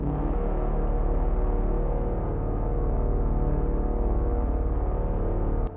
piano-sounds-dev
d2.wav